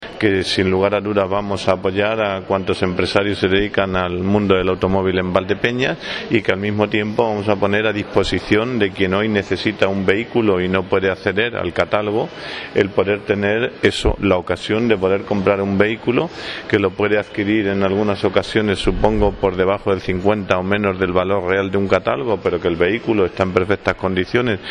En declaraciones a los medios de comunicación, Martín ha querido mostrar el apoyo del Consistorio a los concesionarios y talleres de vehículos y ha señalado que este salón no se celebraba desde hace una década “porque es el sector el que ha ido marcando el paso”, por lo que incidido en que en estos tiempos de crisis era el momento de reactivar este salón de oportunidades.